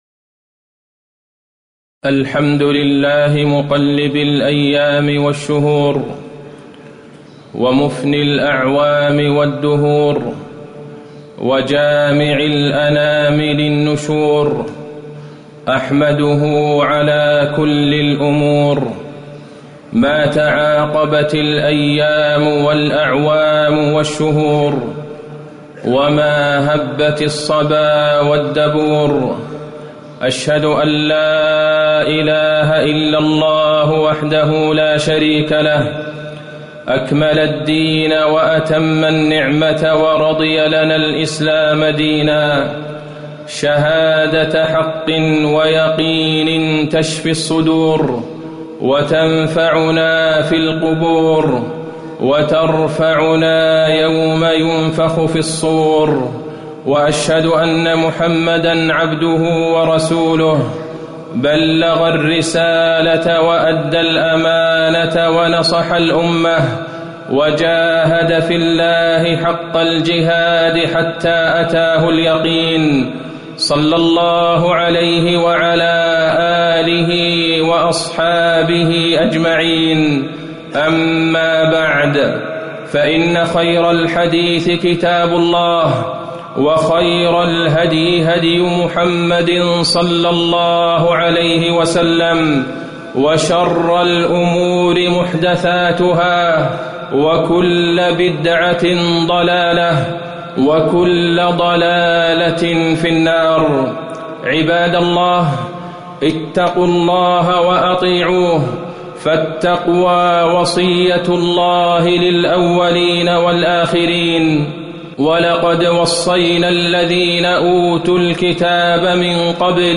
خطبة رجب بين الاتباع والابتداع وفيها: تكليف الإنسان، تفضيل الأشهر الحرم على غيرها، وشروط قبول العبادة، هل هناك فضل خاص لشهر رجب؟
تاريخ النشر ١٠ رجب ١٤٣٨ المكان: المسجد النبوي الشيخ: فضيلة الشيخ د. عبدالله بن عبدالرحمن البعيجان فضيلة الشيخ د. عبدالله بن عبدالرحمن البعيجان رجب بين الاتباع والابتداع